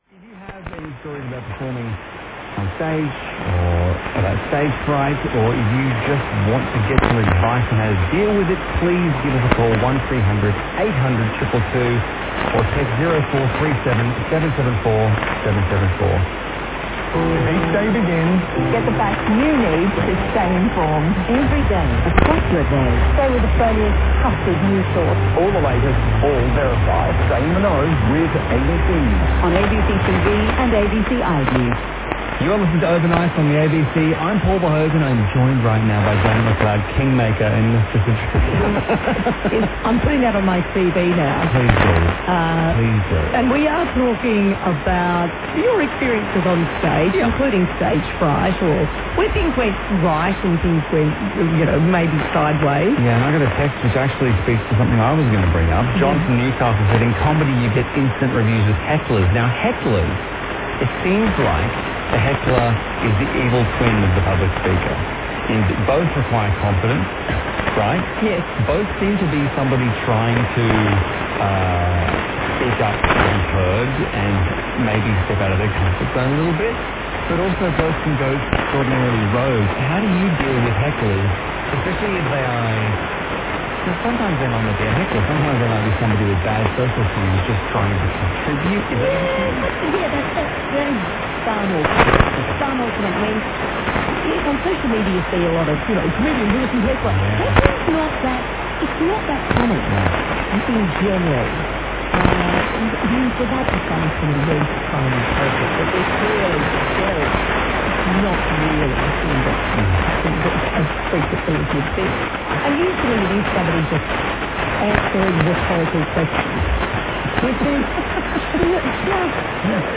02時台のABC-4QDが8割方安定して聞こえており他に浮気し難い状態でした。
東京湾荒川河口ではABC-4QDはホント良く聞こえます。
<受信地：東京都江東区新砂 東京湾荒川河口 RX:ICF-SW7600GR ANT:Built-in bar>
※00:17-00:32 ABC スポットCM
※06:47-07:30 ♪California Dreamin' - The Mamas & The Papas.